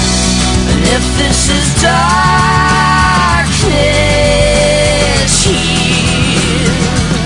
• Ska